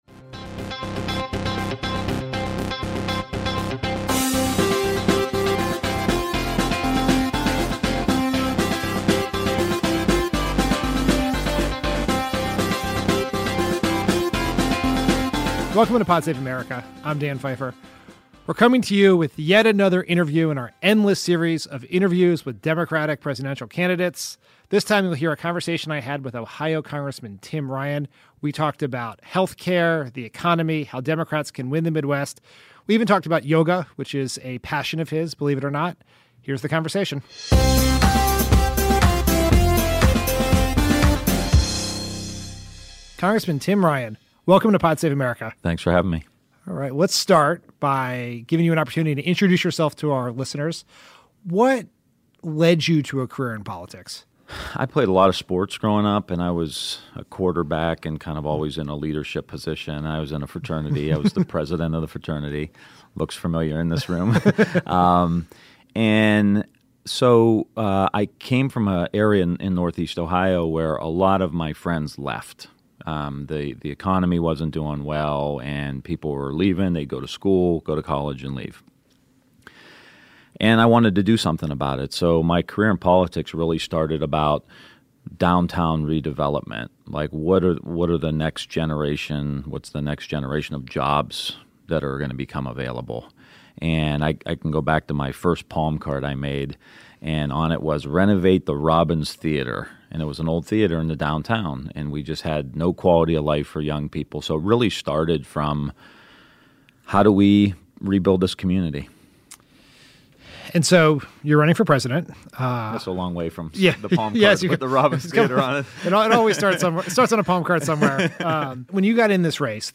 Ohio Congressman Tim Ryan sits down with Dan Pfeiffer at Crooked HQ in Los Angeles to discuss his economic agenda, how Democrats can retake the Midwest and his passion for mindfulness.